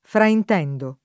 fraintendere [fraint$ndere] v.; fraintendo [